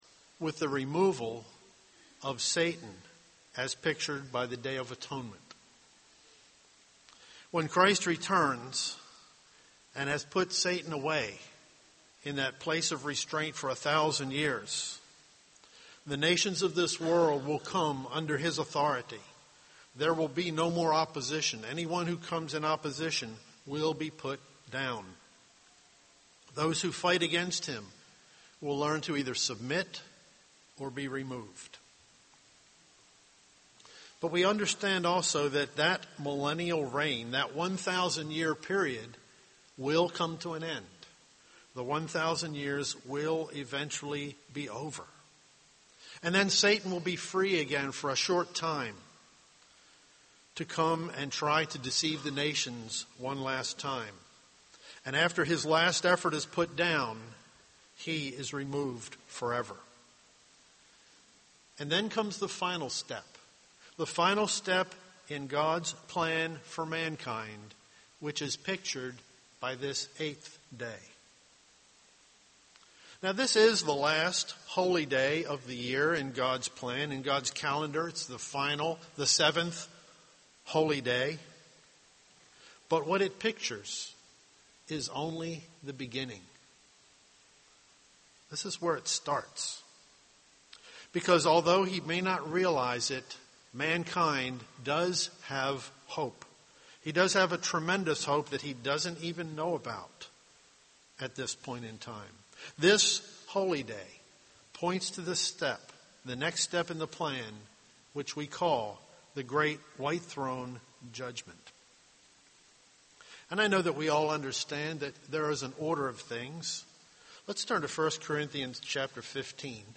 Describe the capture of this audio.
This sermon was given at the Jekyll Island, Georgia 2012 Feast site.